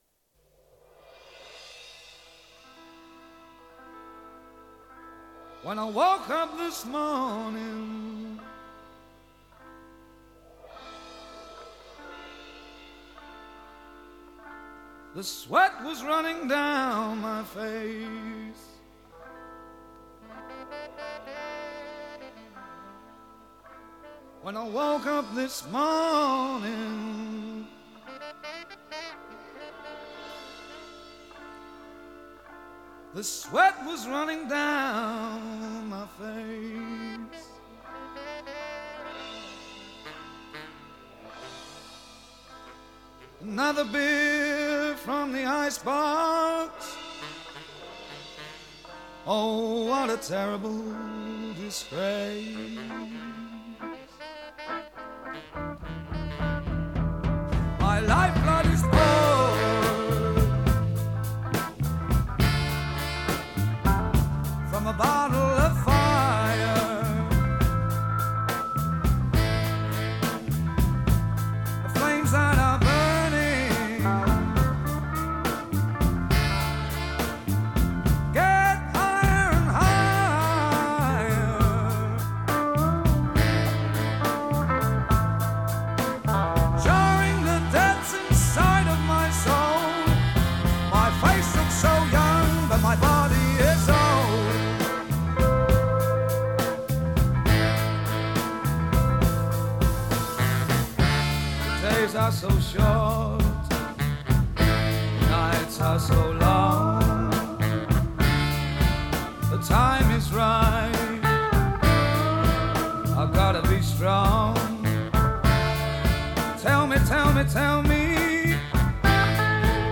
Утренний блюзик...